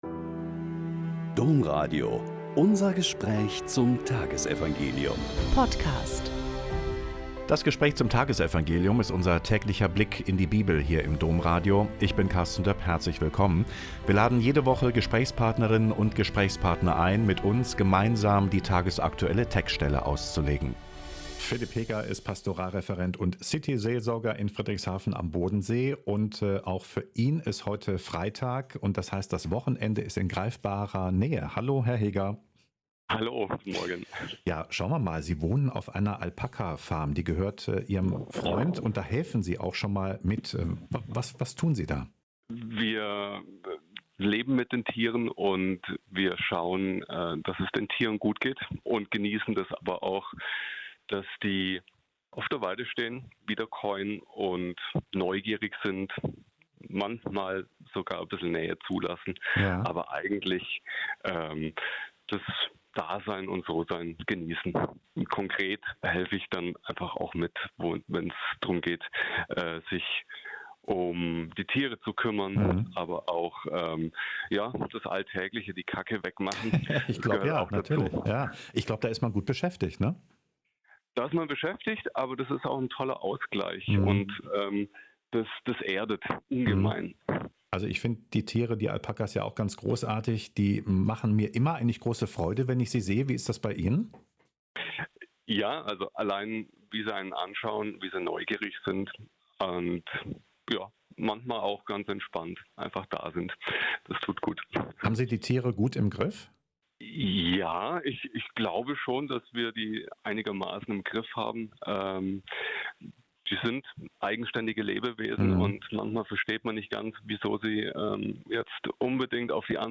Lk 8,1-3 - Gespräch